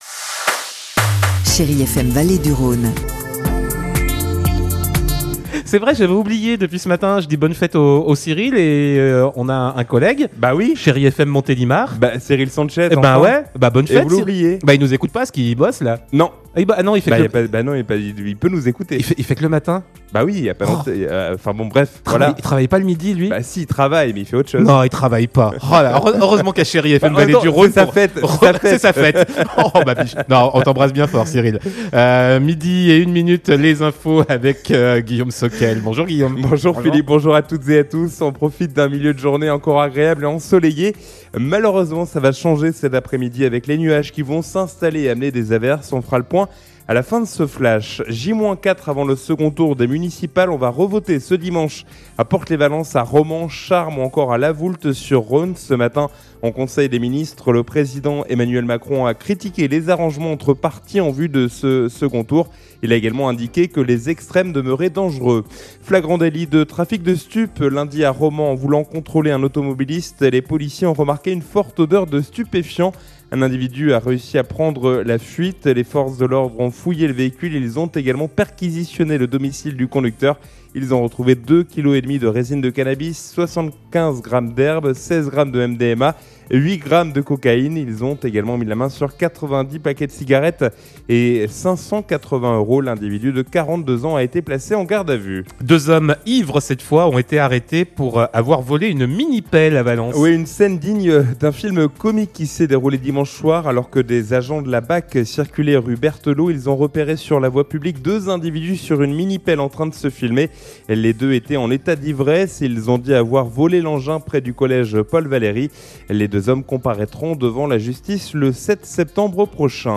Mercredi 18 mars : Le journal de 12h